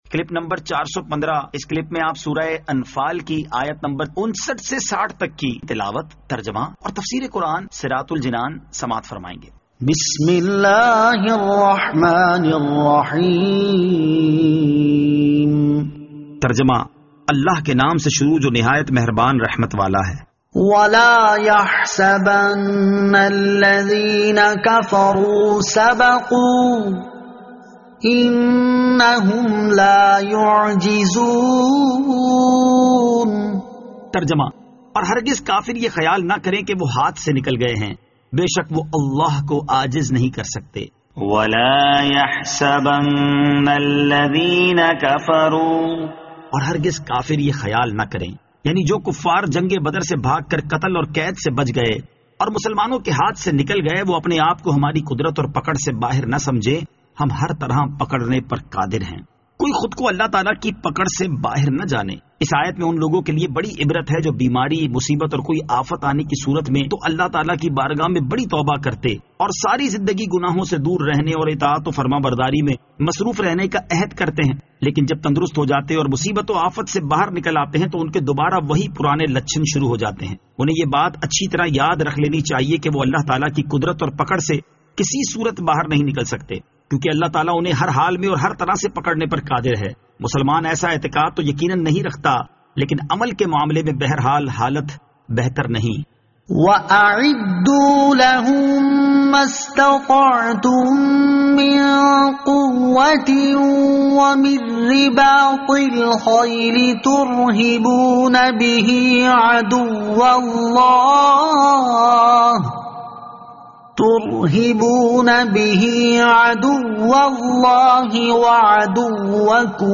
Surah Al-Anfal Ayat 59 To 60 Tilawat , Tarjama , Tafseer